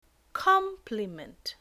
Complement (noun) is pronounced with the stress on the first syllable.
/kom.pli.ment/